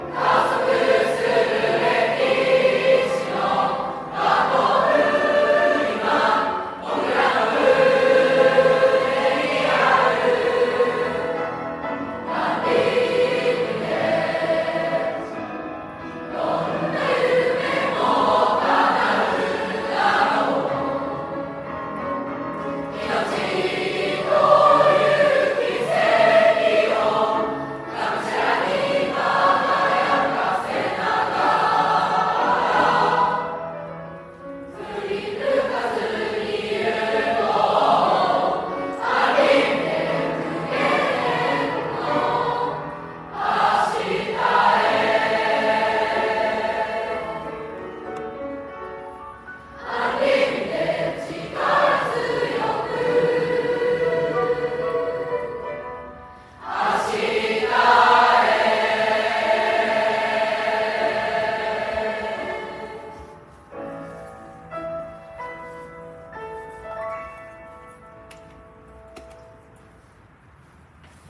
3月18日 49期生 １年生合唱コンクール
学年合唱 「 unlimited 」 １年生学年合唱 ⇐合唱の一部が聞けます。